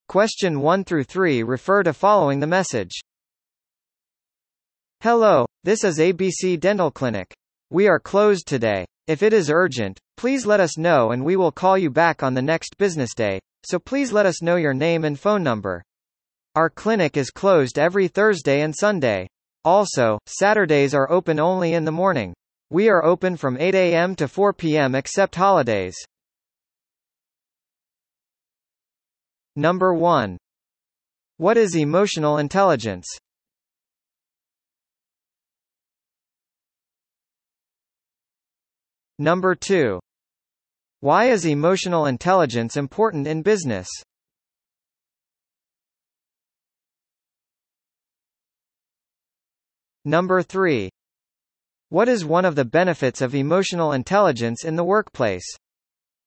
リスニング問題です。